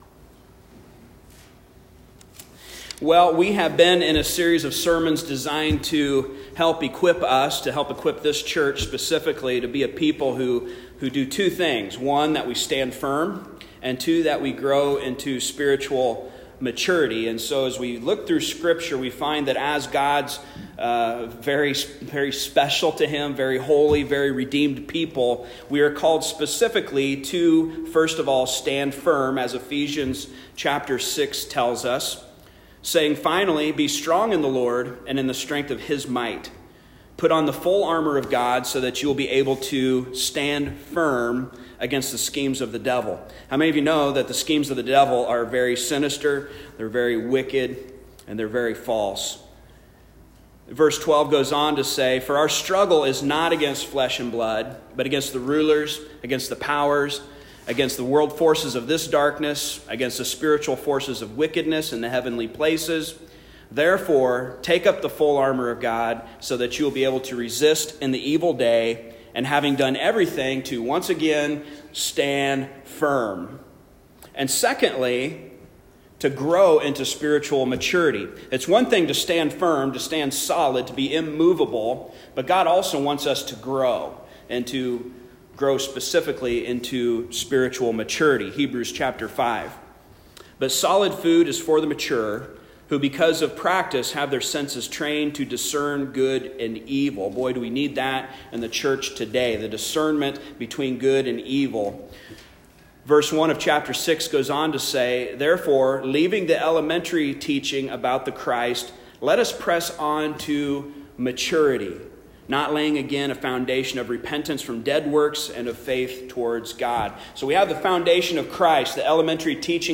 Passage: Psalm 119 Service Type: Sunday Morning